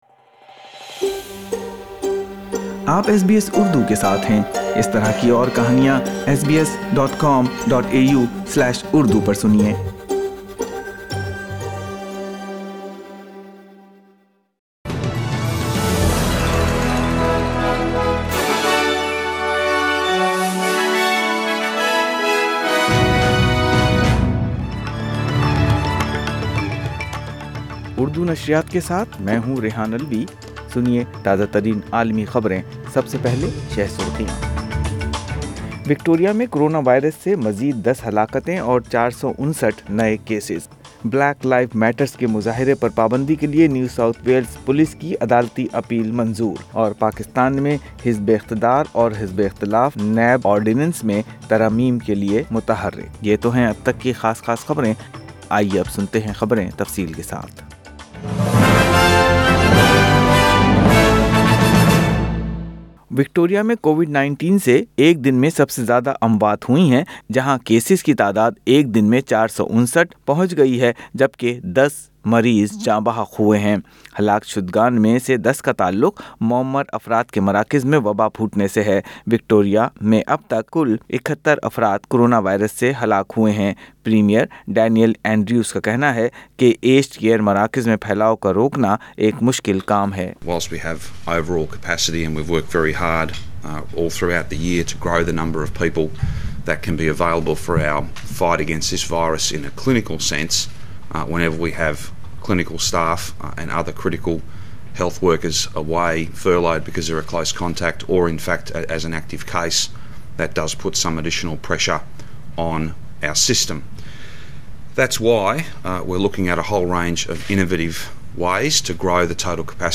اردو خبریں 26 جولائی 2020